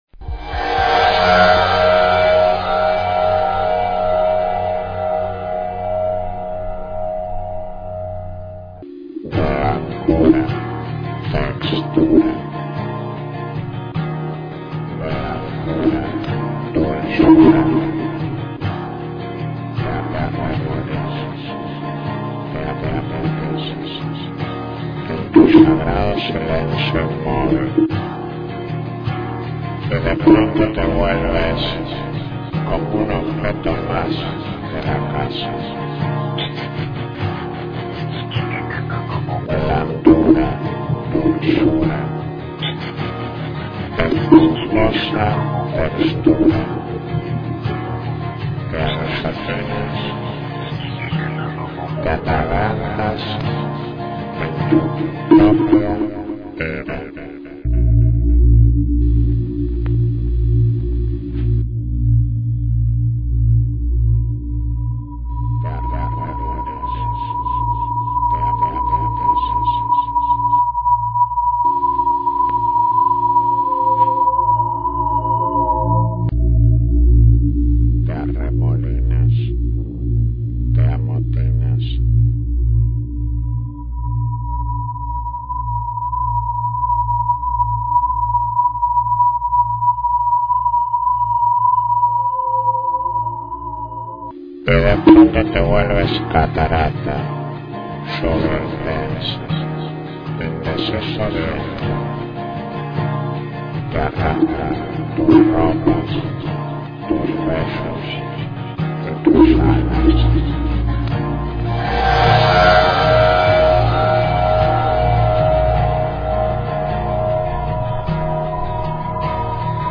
acoustic remix